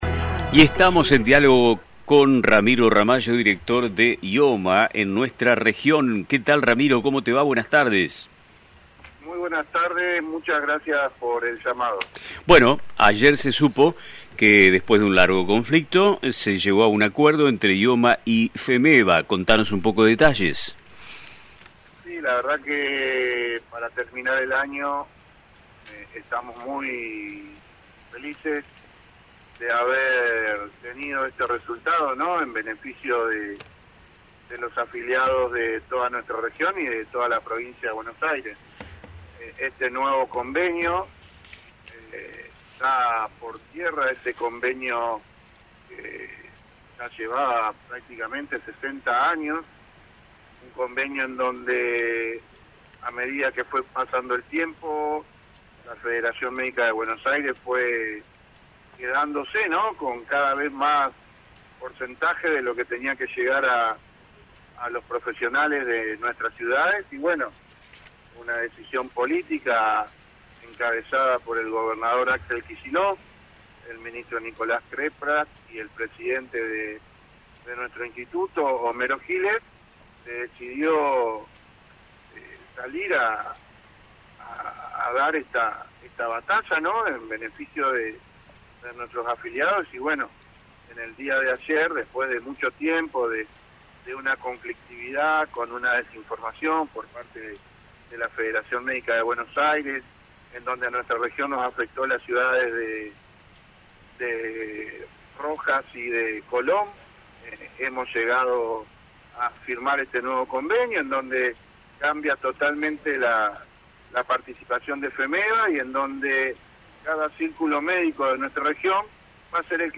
En una reciente entrevista en el programa Nuestro Tiempo, emitido por LT35 Radio Mon Pergamino